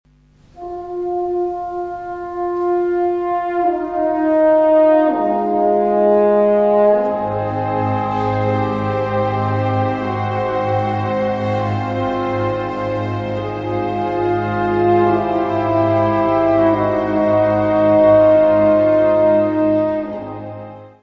Symphonic ode